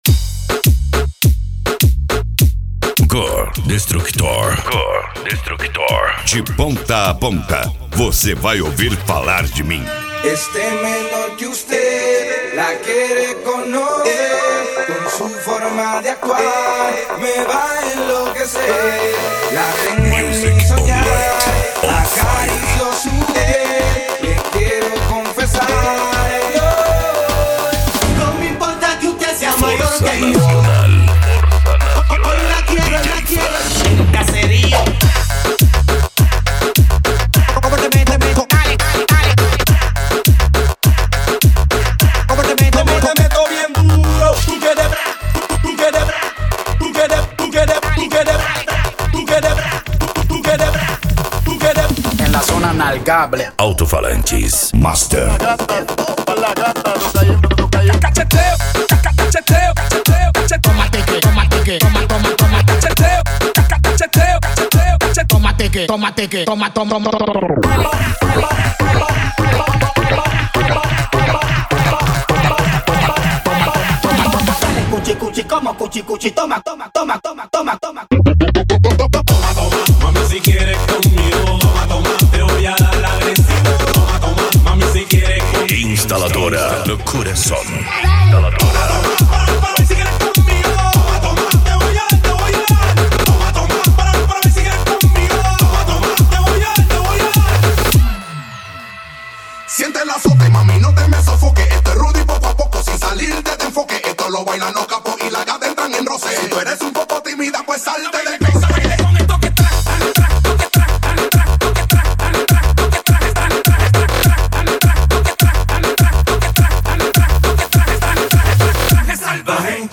Bass
Funk
Remix